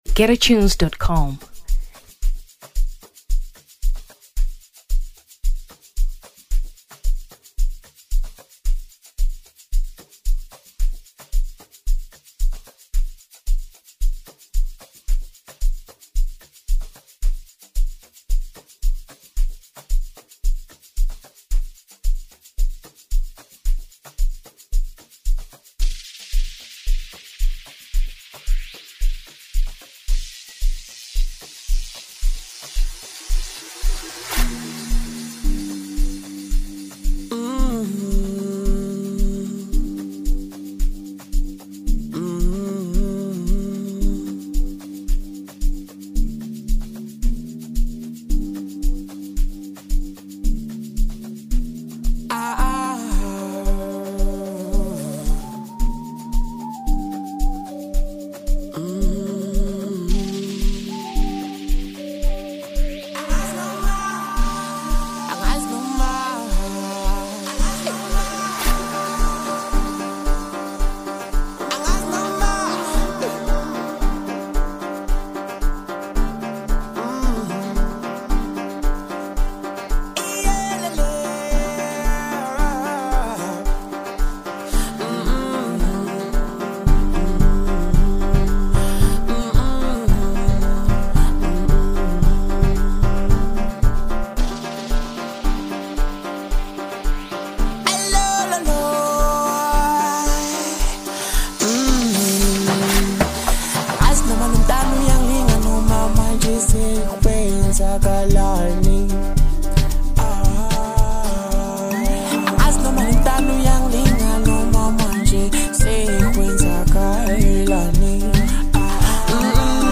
Amapiano 2023 South Africa